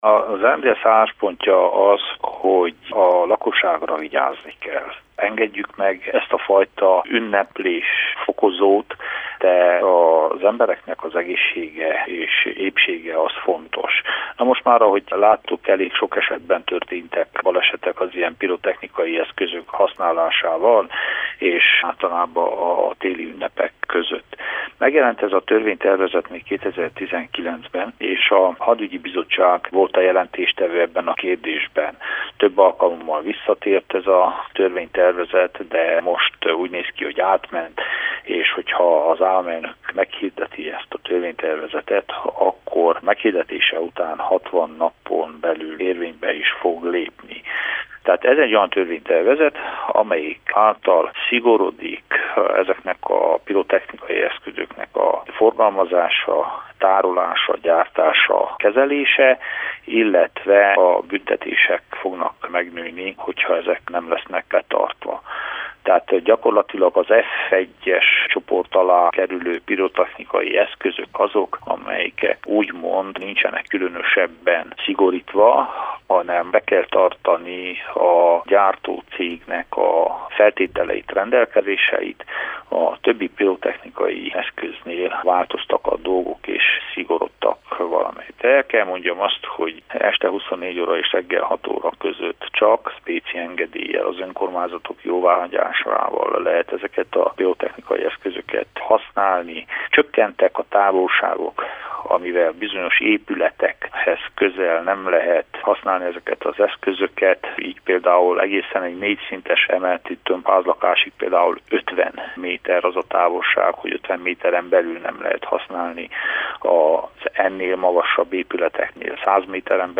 A szabálysértések szankcionálásáról és a biztonsági előírásokról a parlament ipari bizottságának a tagját, Bende Sándor képviselőt kérdeztük.